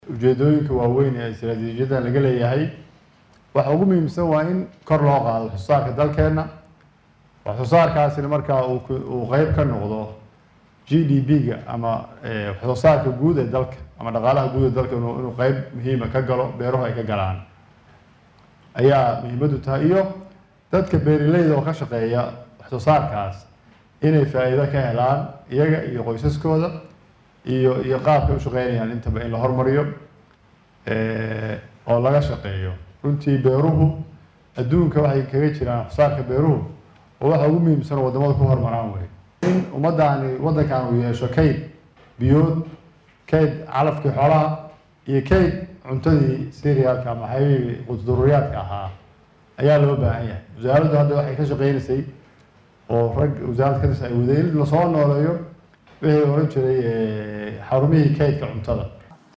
Wasiirka wasaaradda beeraha iyo waraabka ee xukuumadda federaalka Soomaaliya Saciid Xuseen Ciid ayaa maanta furay shirka ansixinta istaraatiijiyadda horumarinta beeraha. Xilli uu hadal kooban ka jeediyay halkaasi ayuu wasiirku faahfaahin ka bixiyay ujeedada laga leeyahay geeddisocodkan.